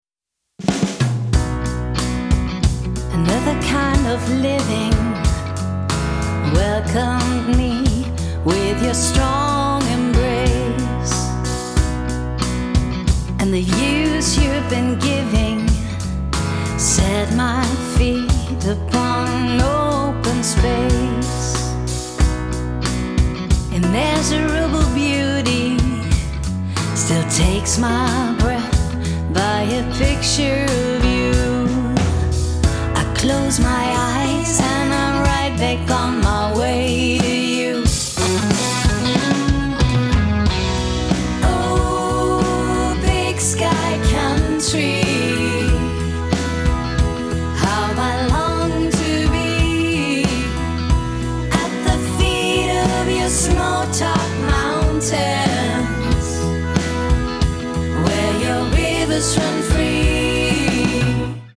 tief in der Tradition des Blues, Rock und Soul verwurzelt